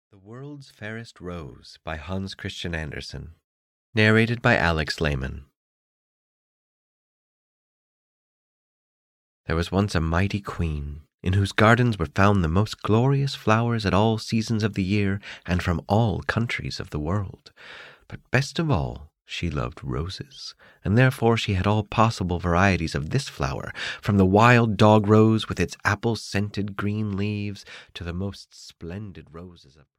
The World's Fairest Rose (EN) audiokniha
Ukázka z knihy